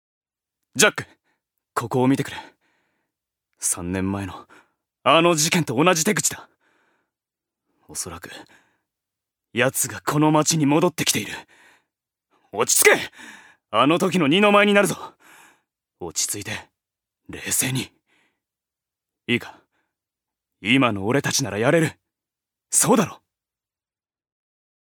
所属：男性タレント
音声サンプル
セリフ２